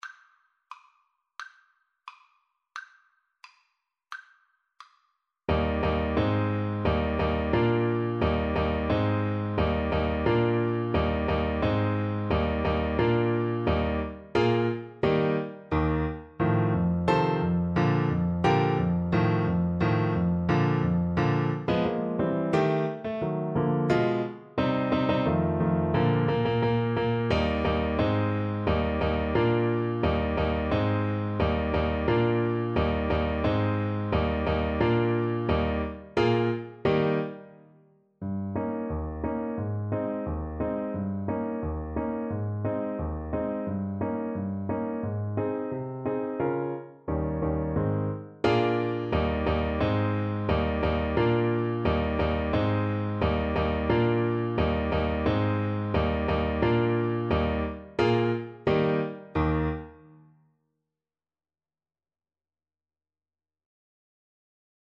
~ = 88 Stately =c.88
Classical (View more Classical Clarinet Music)